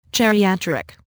Click here to hear the pronunciation of geriatric.